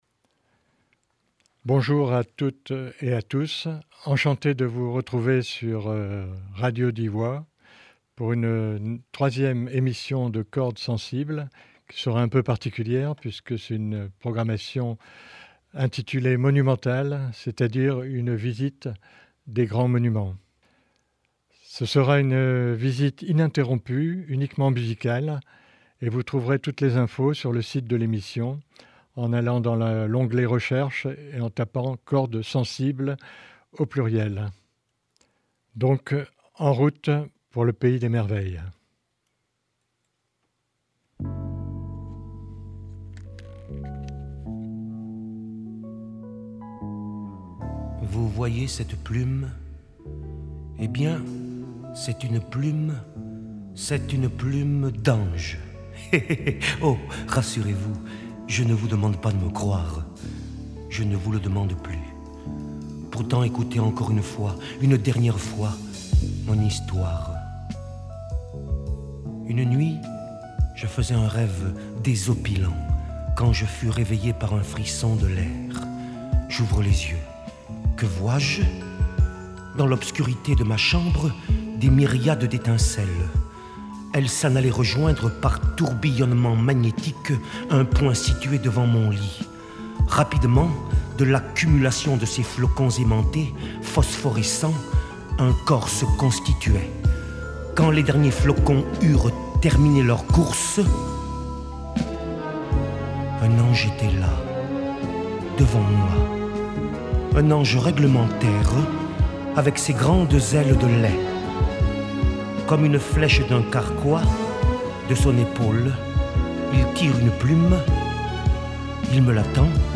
blues , chanson , folk , jazz , poesie , pop , rock , soul